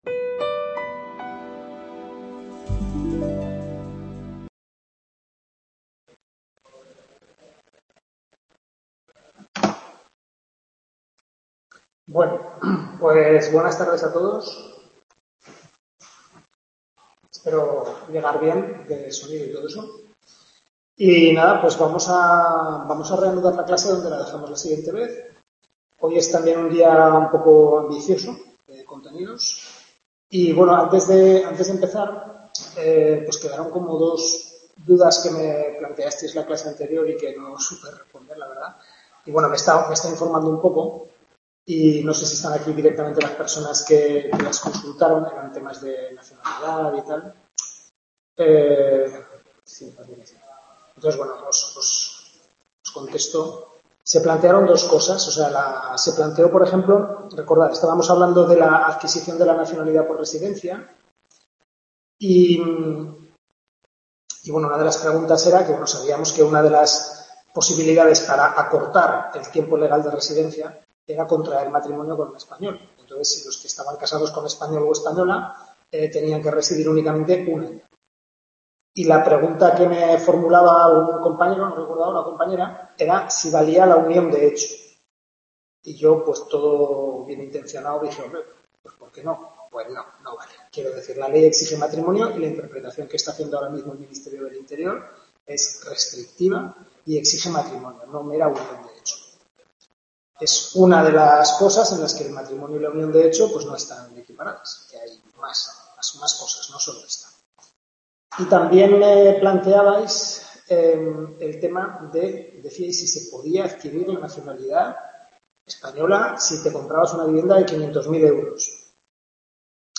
Tutoría de Civil I